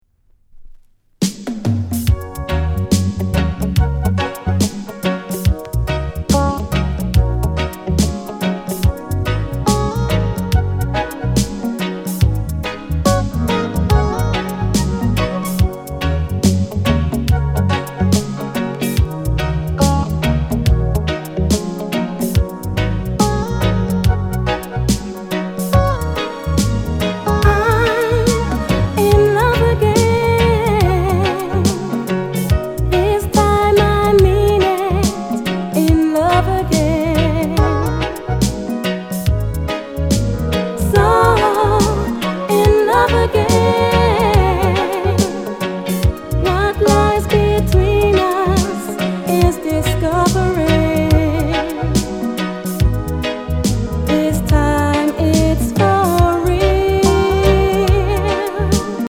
NICE LOVERS ROCK